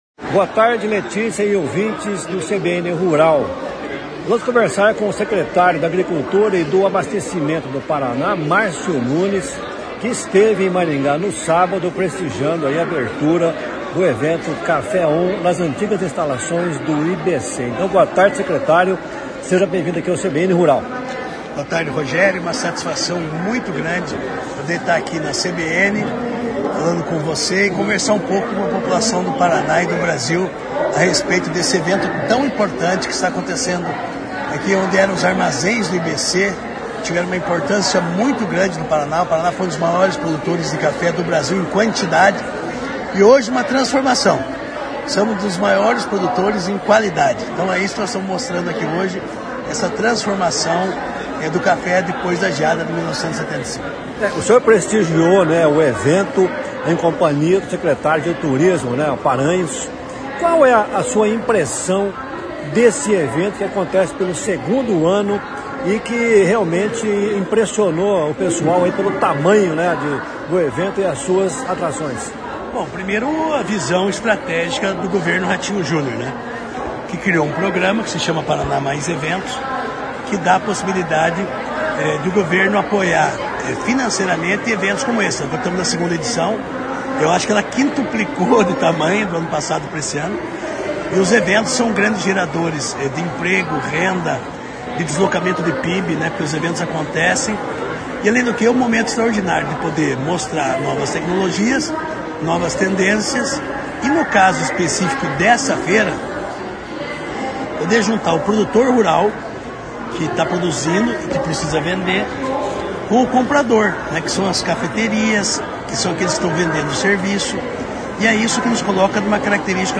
Secretário da Agricultura fala da produção de cafés especiais
Em visita a Maringá no sábado, onde prestigiou a abertura do evento Ca Fé On, realizado nas antigas instalações do Instituto Brasileiro do Café (IBC) na Avenida Centenário, o secretário de Agricultura e do Abastecimento do Paraná, Márcio Nunes, falou ao CBN Rural sobre a volta da cafeicultura ao estado, mas com uma nova roupagem: nichos de cafés especiais.